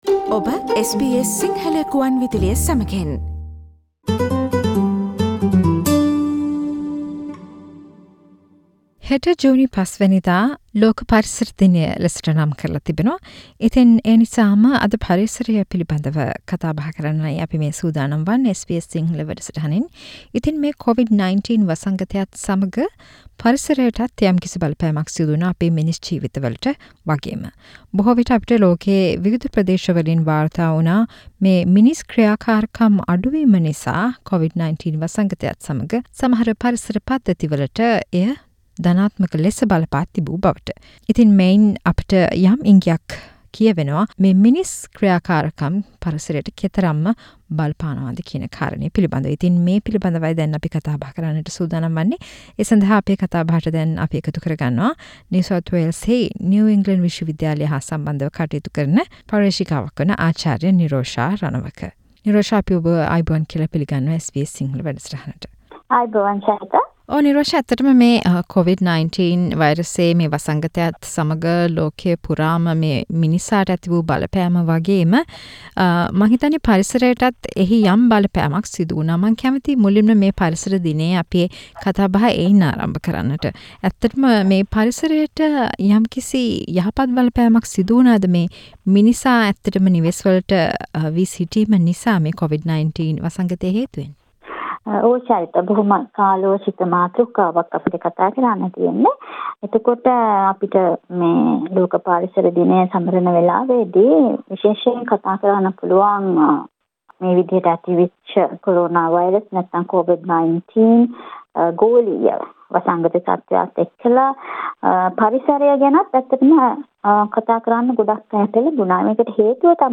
A discussion